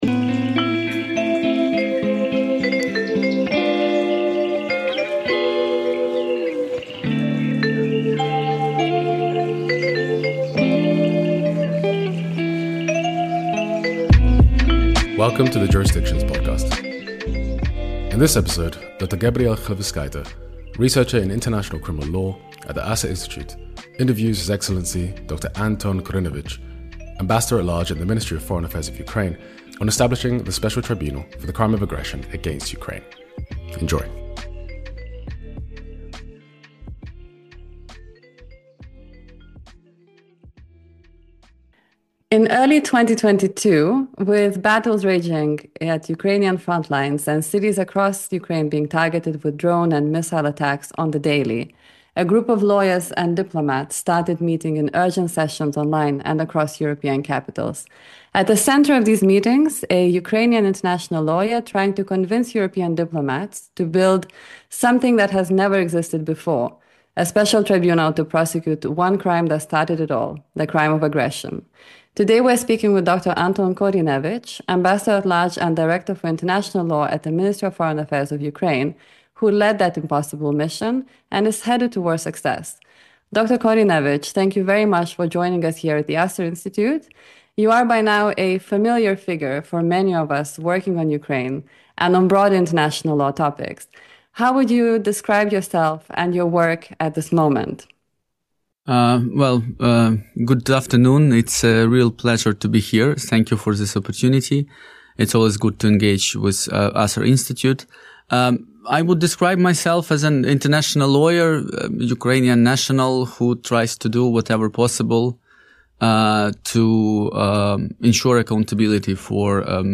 ‘The litmus test of Palestine’ - A conversation with Ammar Hijazi, Ambassador and Head of the Palestinian Mission to the Kingdom of the Netherlands